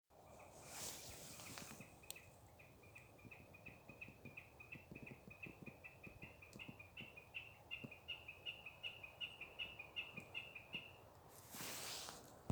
бекас, Gallinago gallinago
Administratīvā teritorijaKrustpils novads
СтатусПоёт